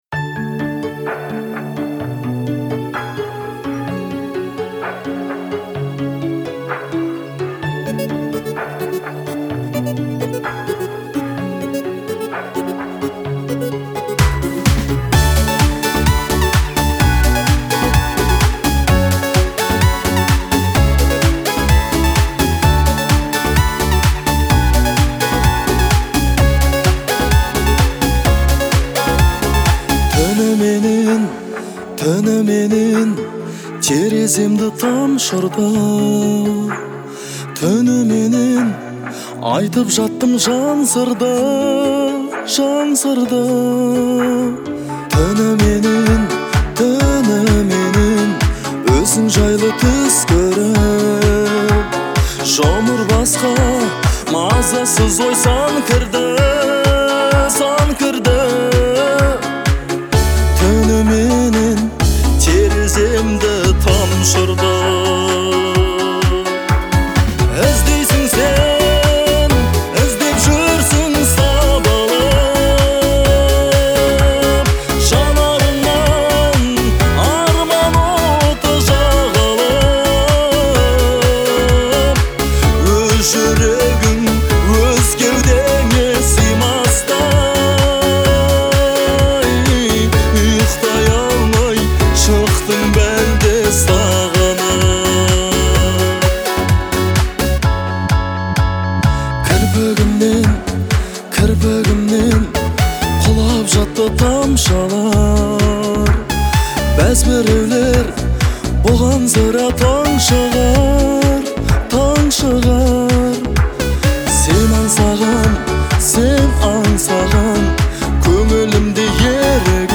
это трек в жанре казахской поп-музыки